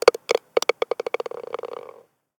ball_in_hole.ogg